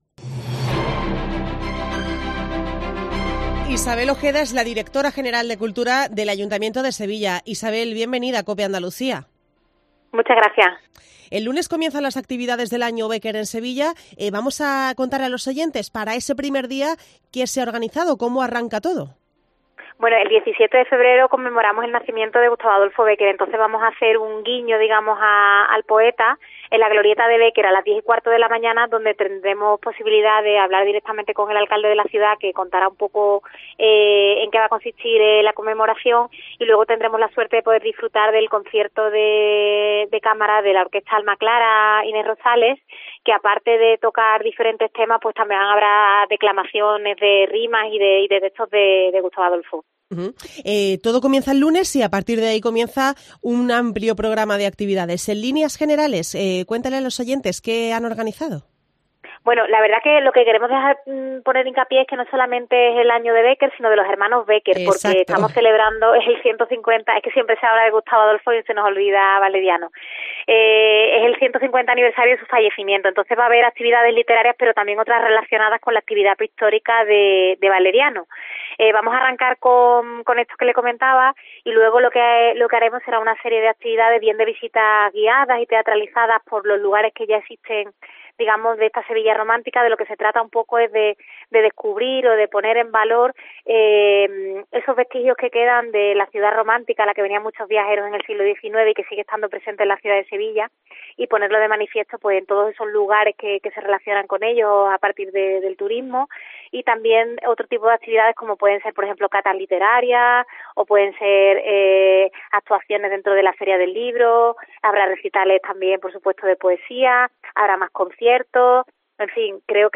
En Directo COPE MÁLAGA